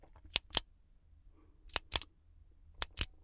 Kugelschreiber-Klicken.
kugelschreiberklicken-UhdG9XTW8DReKpxuVRAGQs.wav